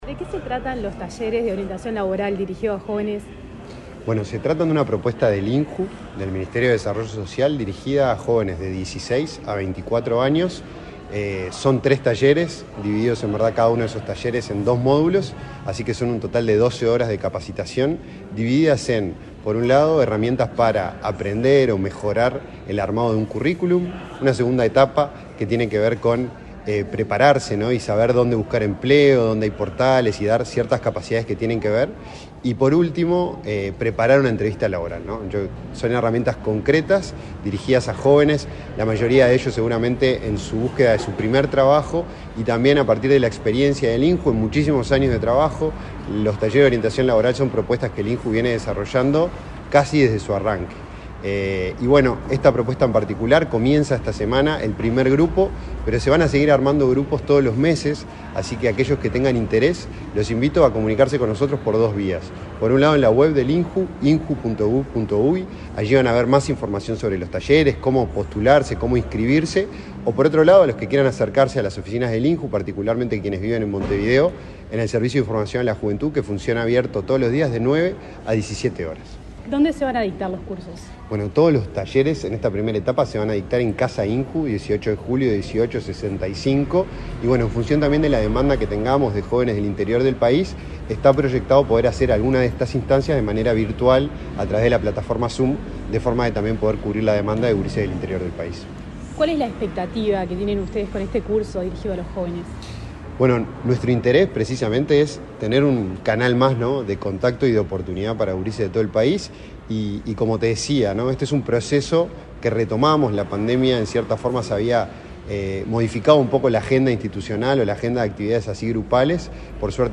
Entrevista al director del INJU, Felipe Paullier